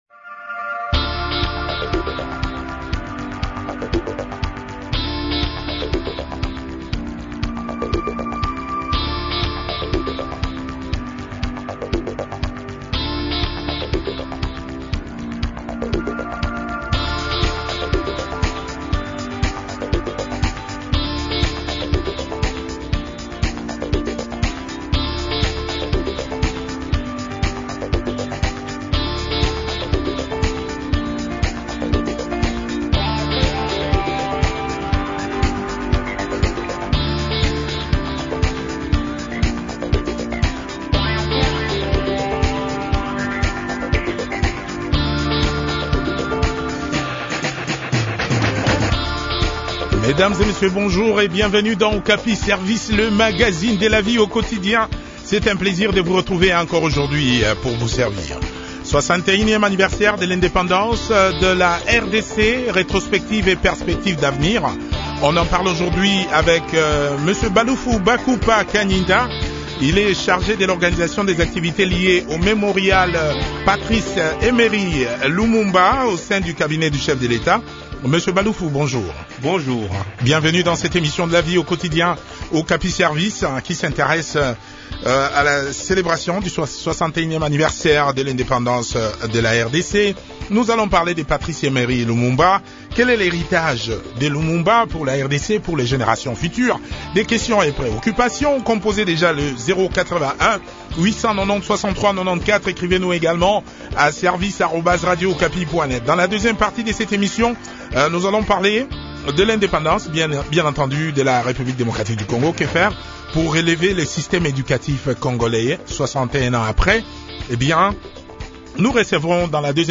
poète, écrivain et cinéaste.